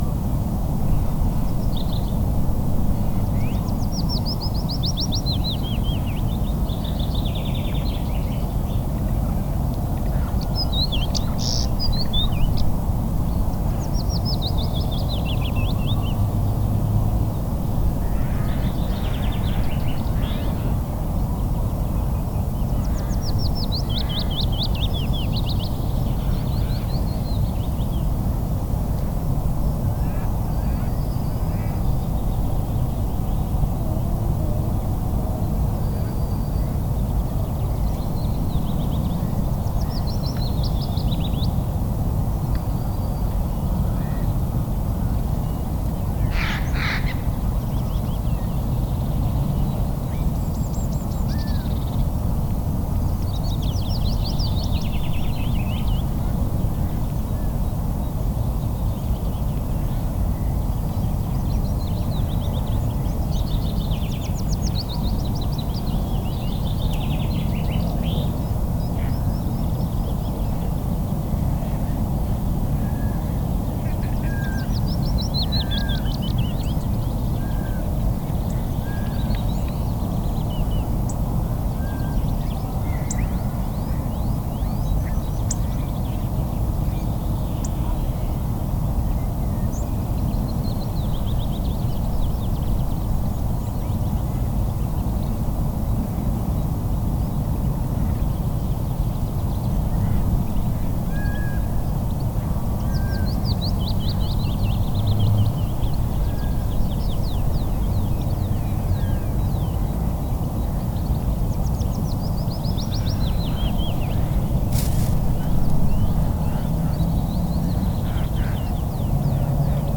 drifting sand area Loonse en Drunense Duinen Netherlands 0957 AM 250404_1067
Category 🌿 Nature
ambiance ambience ambient atmospheric background-sound birds calm drifting-sand-dunes sound effect free sound royalty free Nature